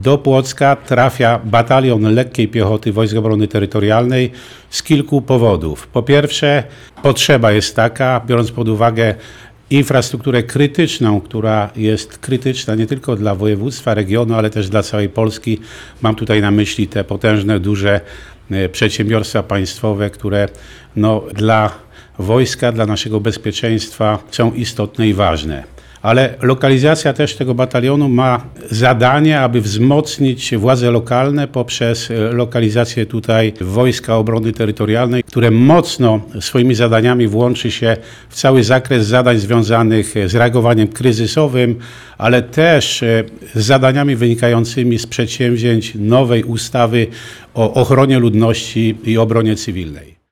W poniedziałek, 15 grudnia, w Starostwie Powiatowym w Płocku odbyła się konferencja prasowa poświęcona podpisaniu aktu notarialnego dotyczącego obecności jednostki Wojsk Obrony Terytorialnej na terenie powiatu płockiego.
– mówił gen. Edward Gruszka.